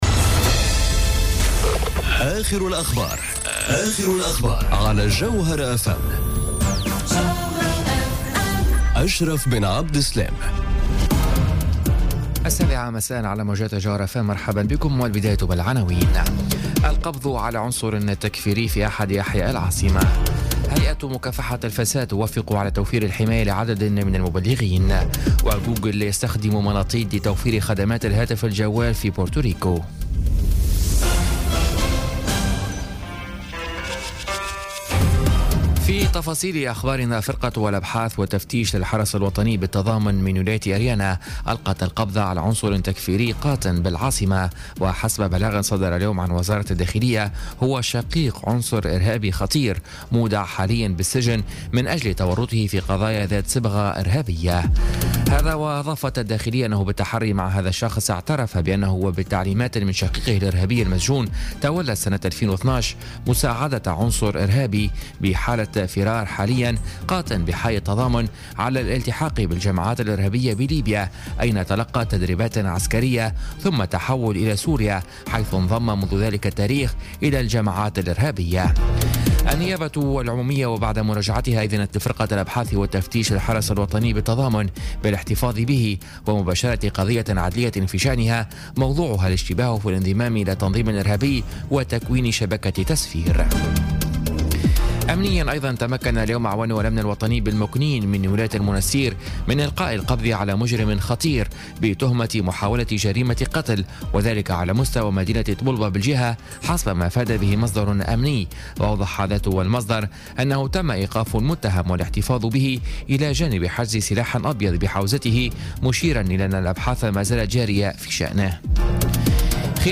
نشرة أخبار السابعة مساء ليوم السبت 7 أكتوبر 2017